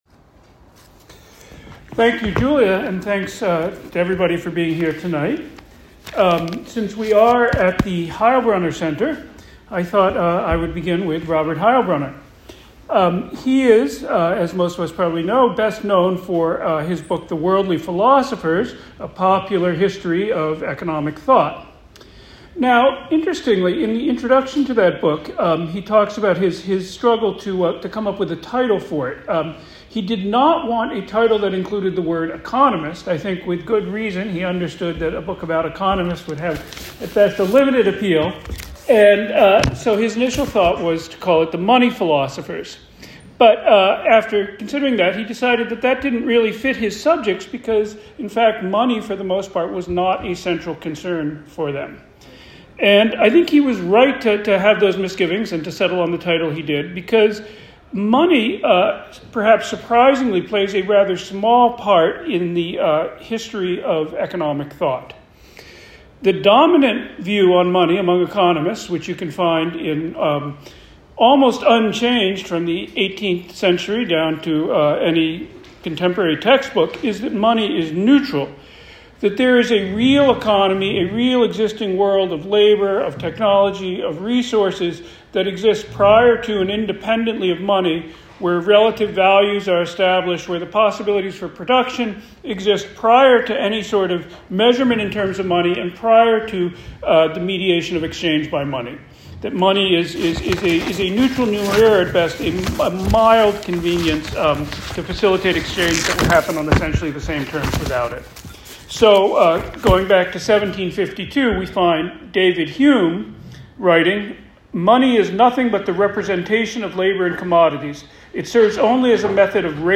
You can listen to the full recording of this talk, including some quite interesting questions from the audience, here: